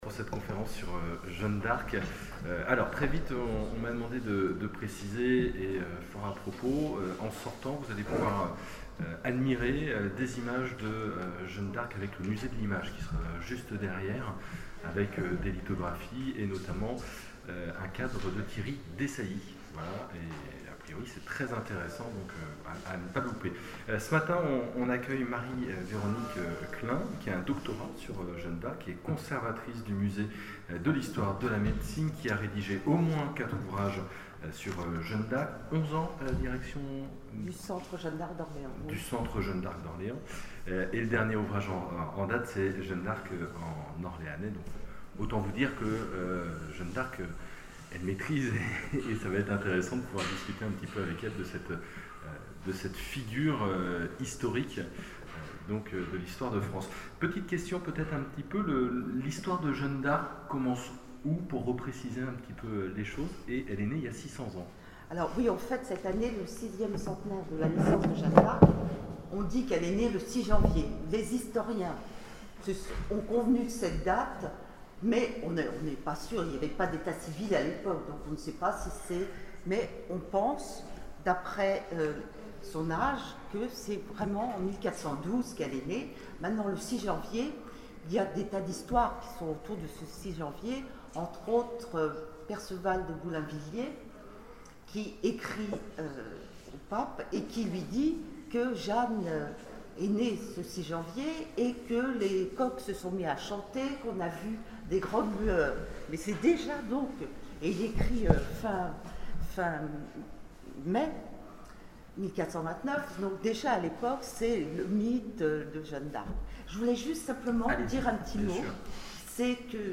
Imaginales 2012 : Conférence Jeanne d'Arc, héroïne nationale